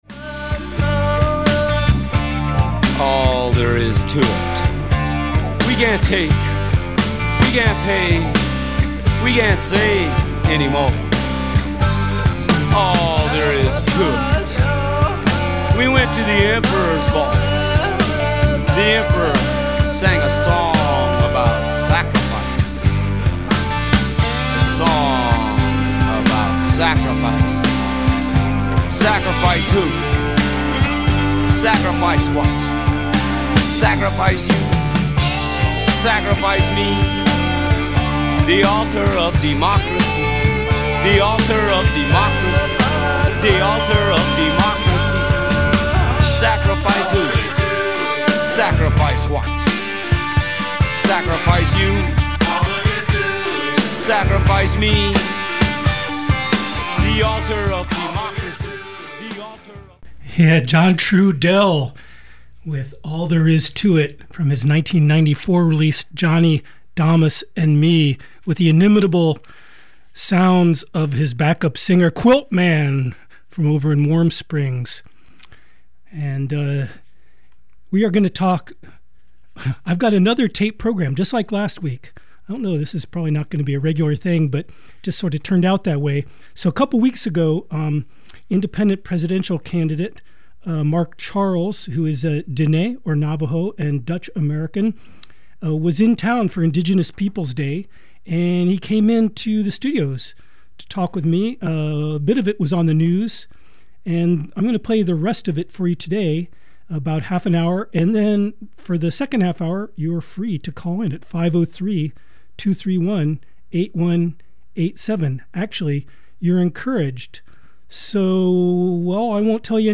Unsettling Truths: A Conversation with Independent Presidential Candidate Mark Charles